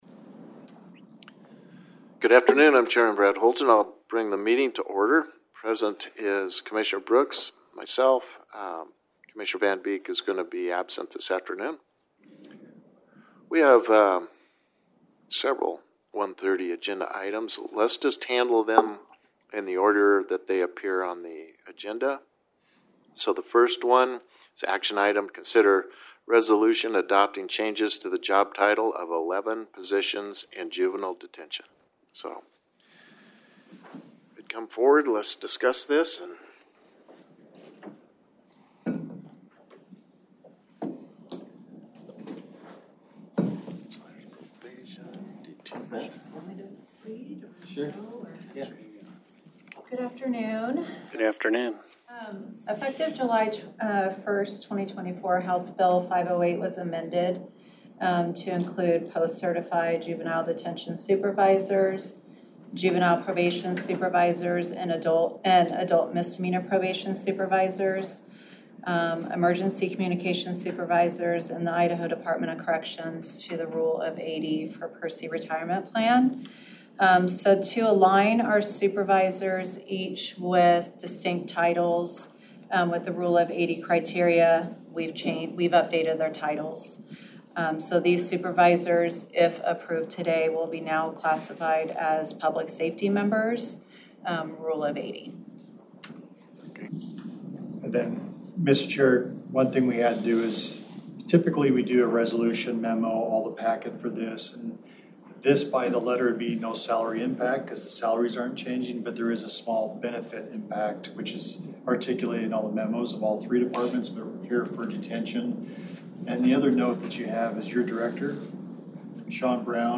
Meeting with County Attorneys for a Legal Staff Update and Consider Action Items: